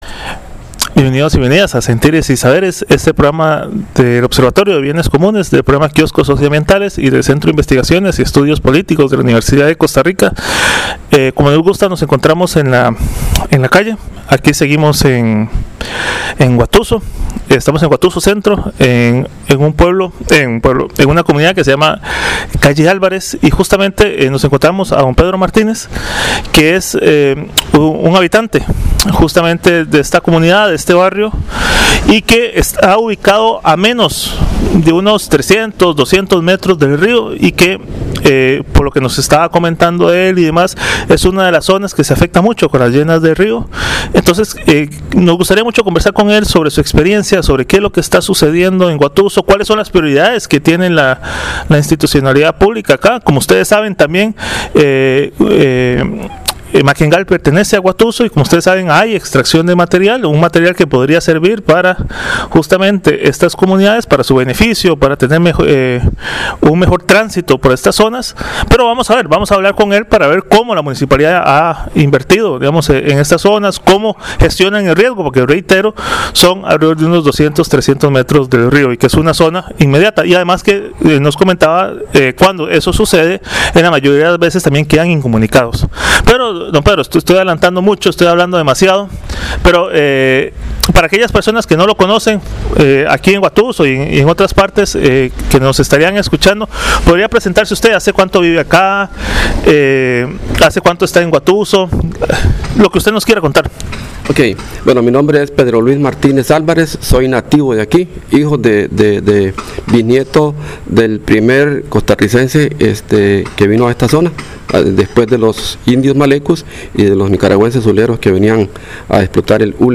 Escuchar esta entrevista es asomarse a la política real, la que se vive en los caminos, en los hogares y en las decisiones que afectan la vida diaria.